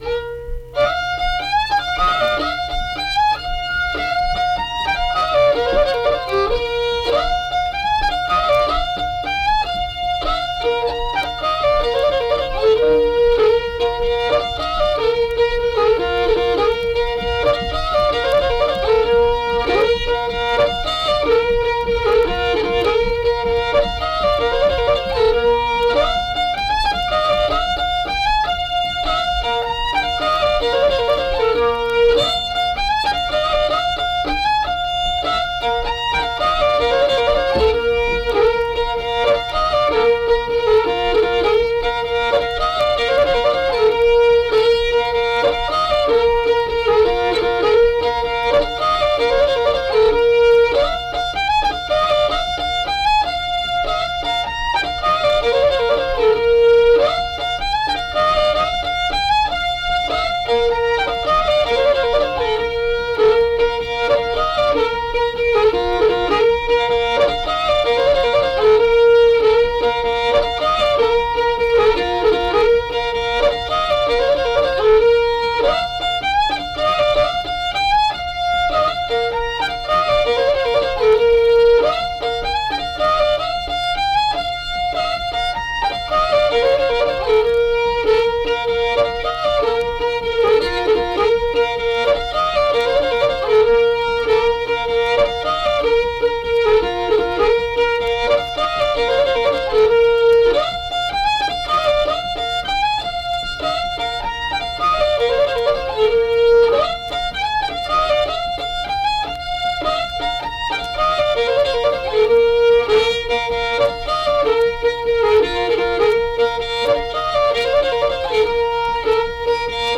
Unaccompanied fiddle music and accompanied (guitar) vocal music performance
Verse-refrain 6(2).
Instrumental Music
Fiddle
Braxton County (W. Va.)